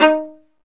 Index of /90_sSampleCDs/Roland - String Master Series/STR_Viola Solo/STR_Vla2 % marc